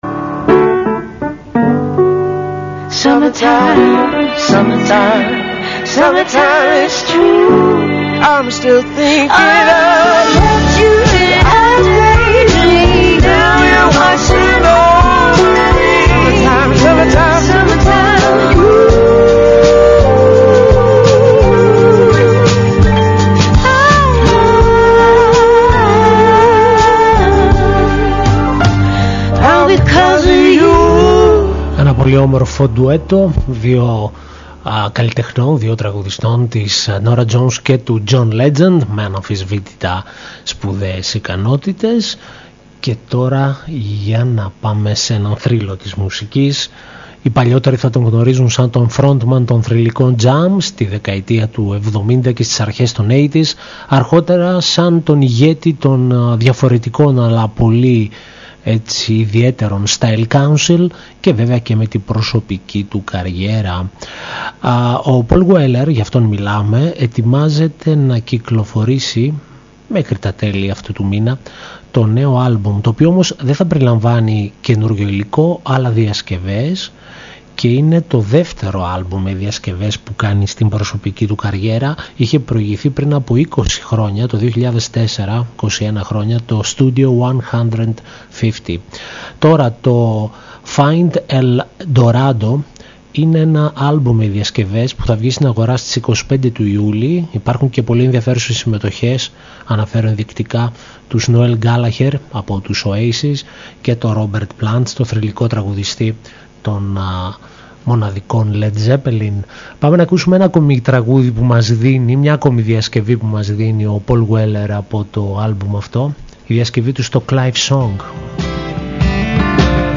Τα τραγούδια που παρουσιάστηκαν στη σημερινή εκπομπή